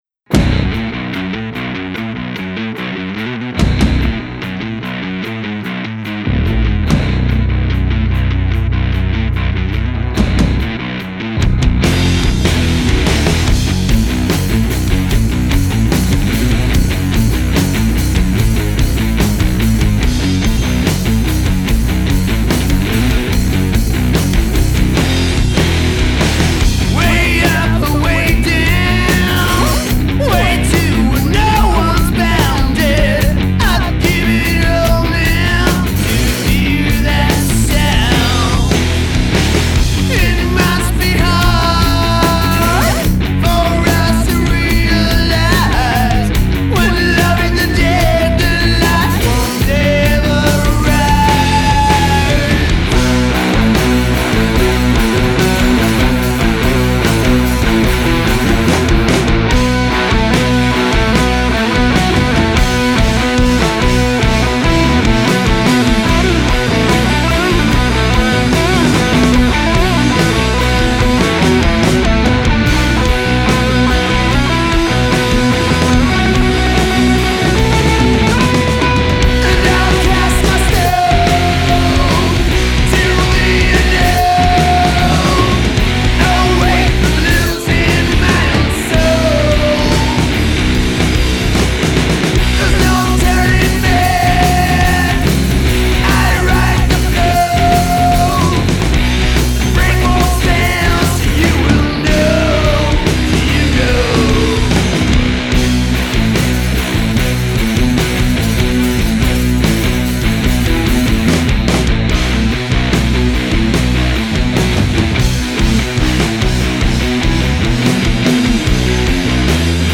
Heavy rockers